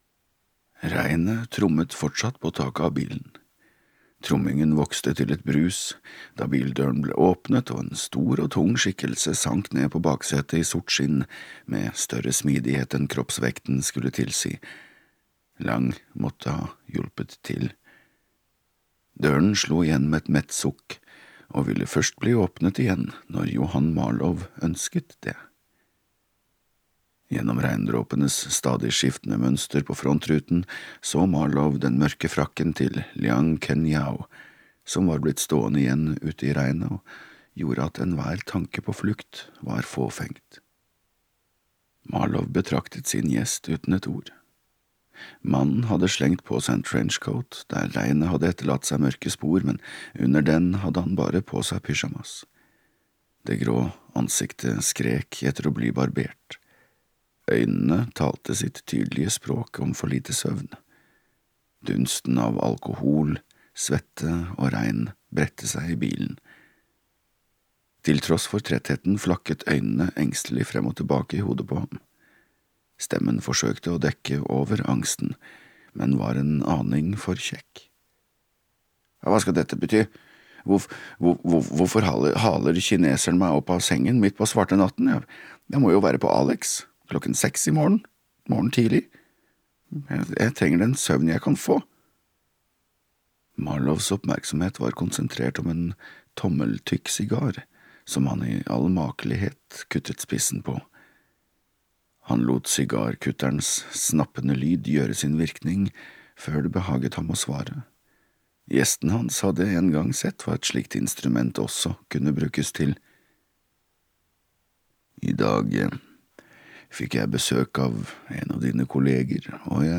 Babylon Berlin - Del 2 (lydbok) av Volker Kutscher